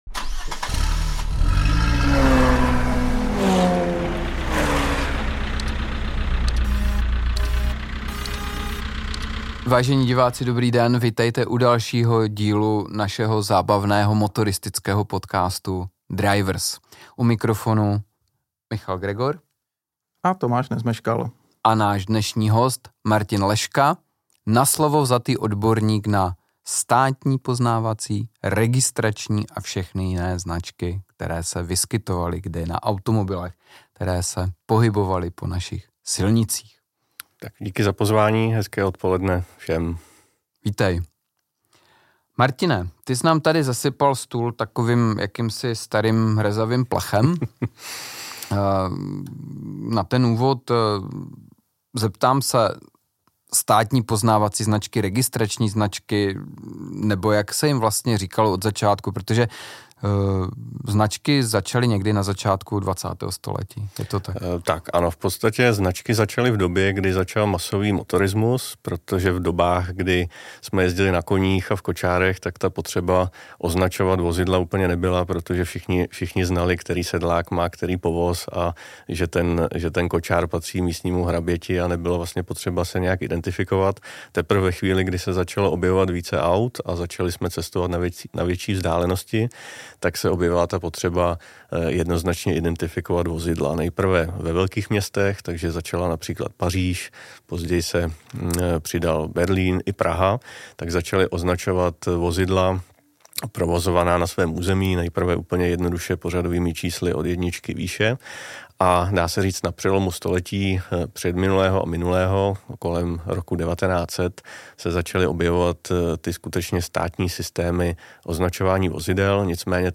Nasmáli jsme se fakt hodně.